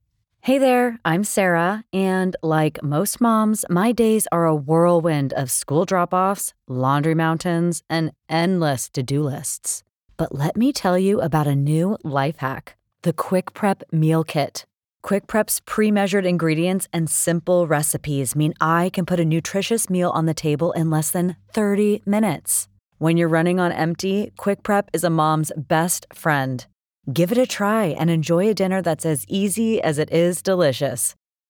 If you're looking for an English American female with an engaging, friendly, warm voice to grab and keep your listener's attention, I'm your gal.
Mom Product Commerical Voiceover
California